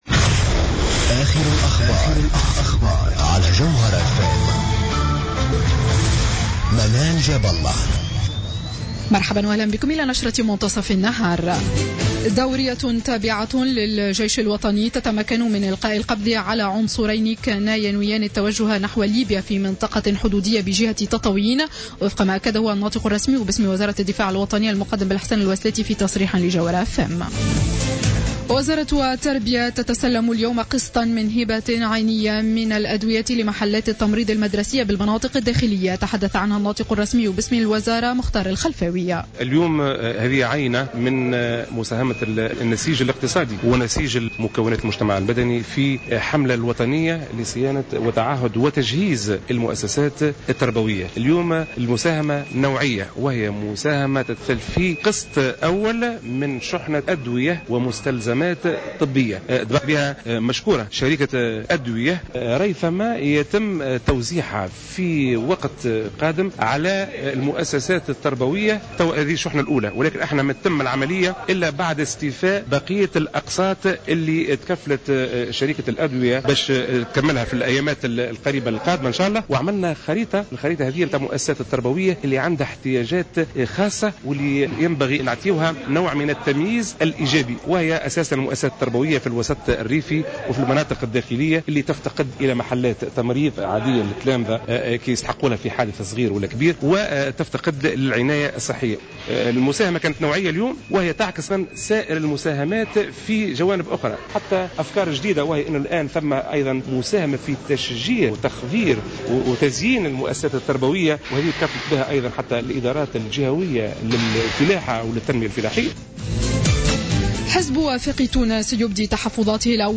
نشرة أخبار منتصف النهار ليوم الثلاثاء 25 أوت 2015